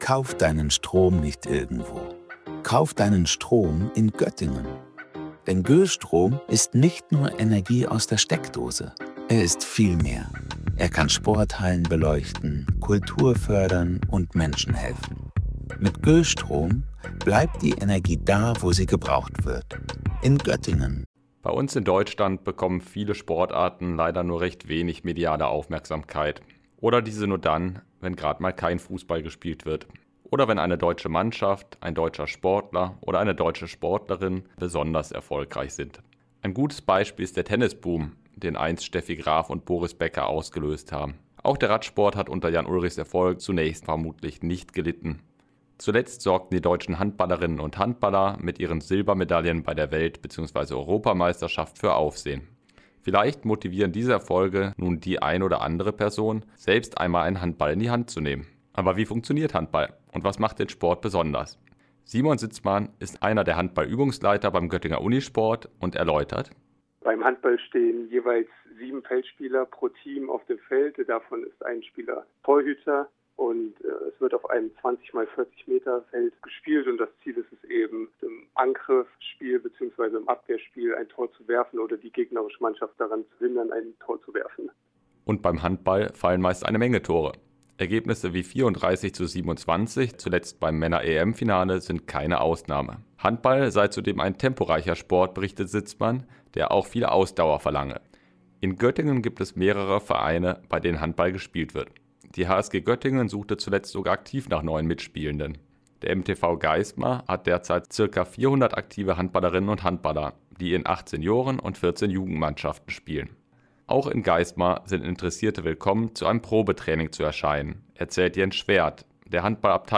Wie genau funktioniert Handball? Und wie und wo kann man hier in der Region Handball spielen? Unser Reporter hat unter anderem mit Vertretern lokaler Handballvereine gesprochen und stellt uns die Sportart näher vor.